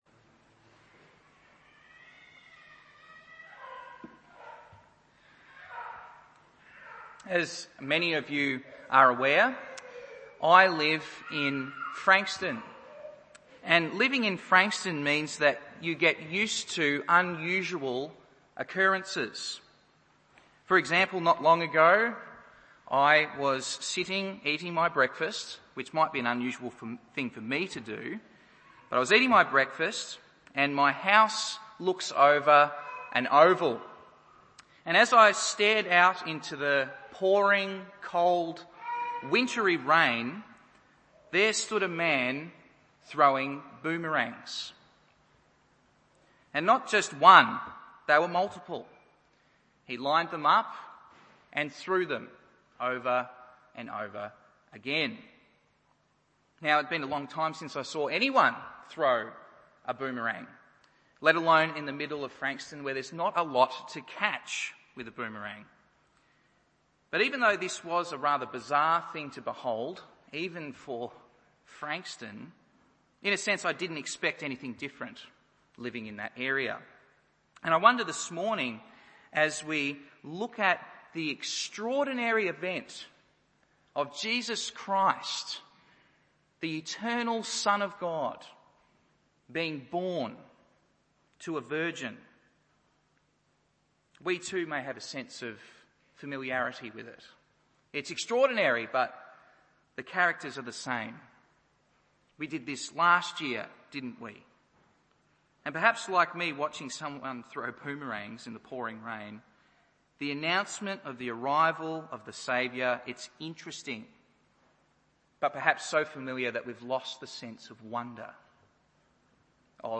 Christmas service 2017 - A World-Altering Birth Announcement